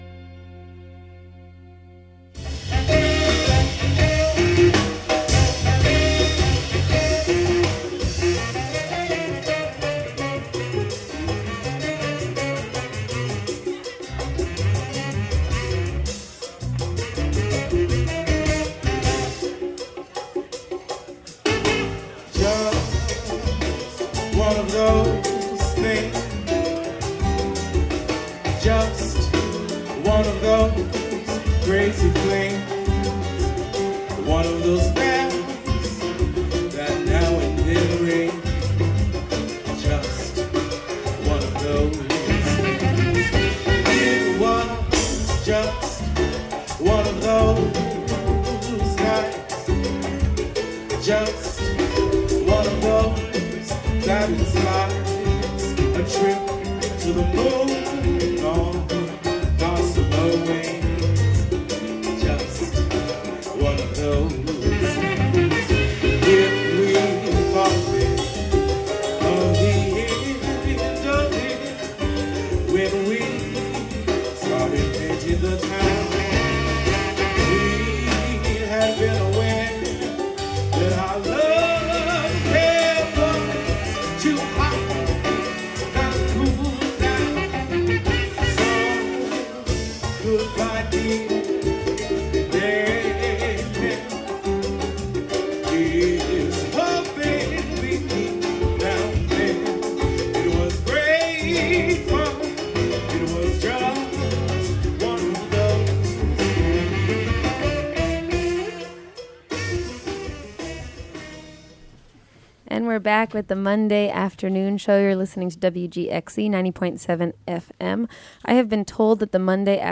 Afro-cuban music, mambo, latin jazz, brazilian beats -- conversation, and more.